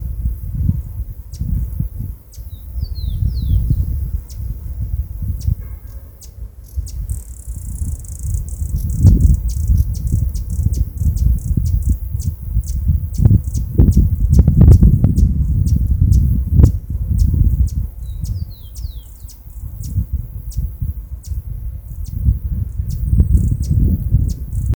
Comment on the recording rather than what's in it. Detailed location: Eco Parque Salto Grande Condition: Wild Certainty: Photographed, Recorded vocal